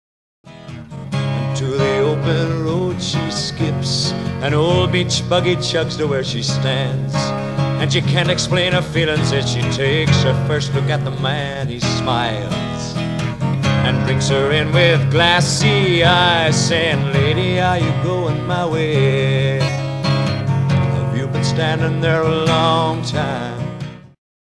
Samples are lower quality for speed.